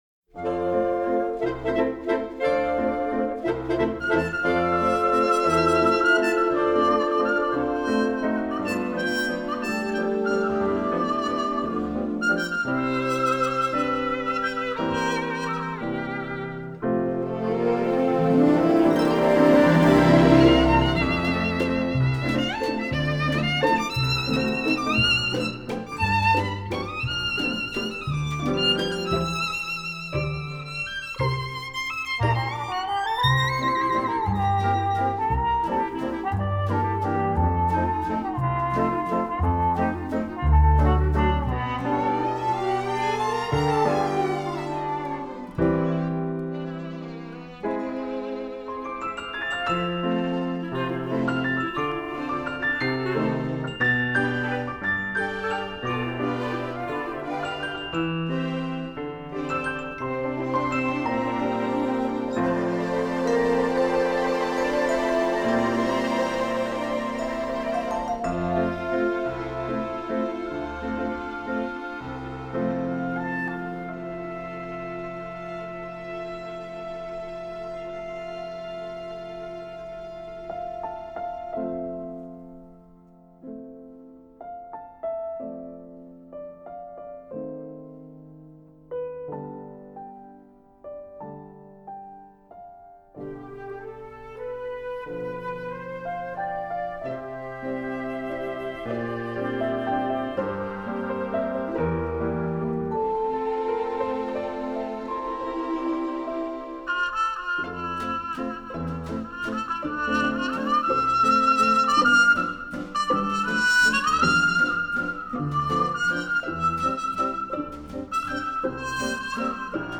2009   Genre: Soundtrack   Artist